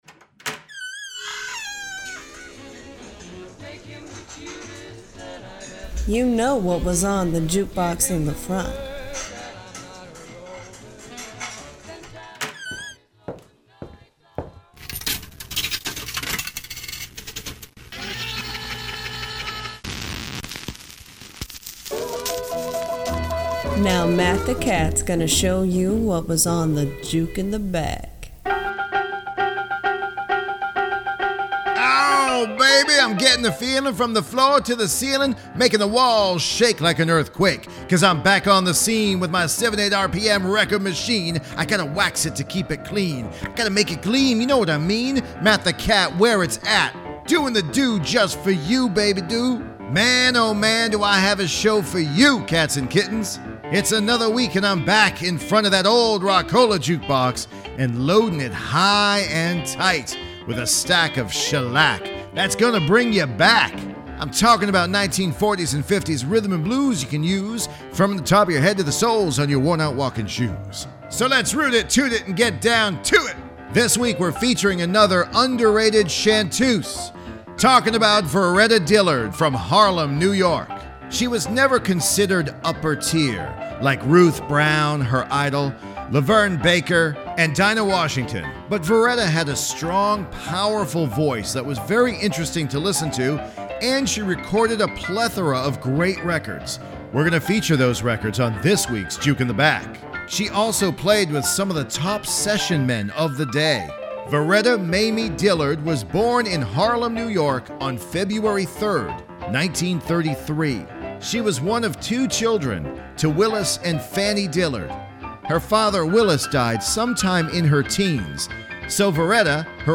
R&B shuffle hits
Both tunes are very catchy and are well-remembered today